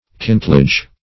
Kintlidge \Kint"lidge\, n. (Naut.)